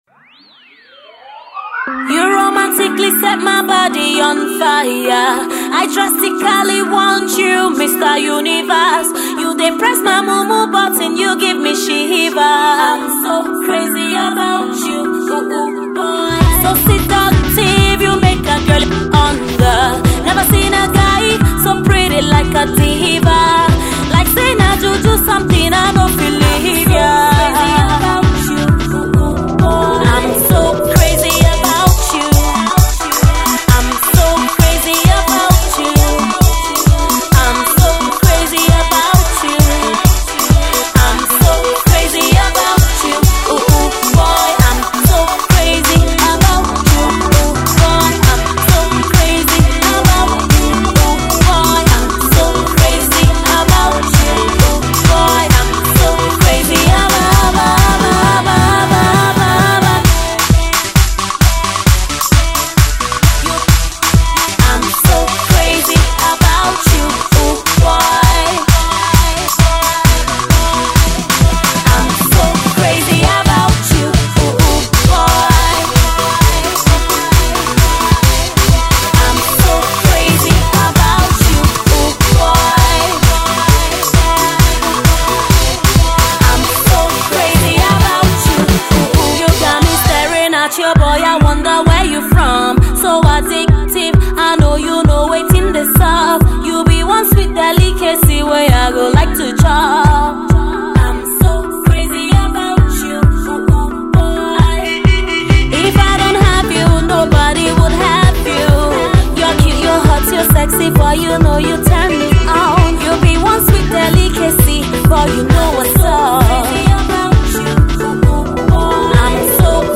two energetic singles